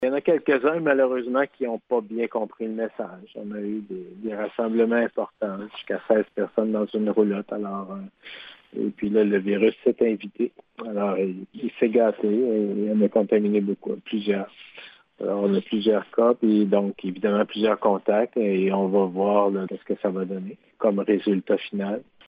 Le Dr Yv Bonnier-Viger donne l’exemple de 16 personnes qui se sont retrouvées dans un camp, dont plusieurs ont finalement été contaminés par le virus :